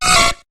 Cri de Fouinar dans Pokémon HOME.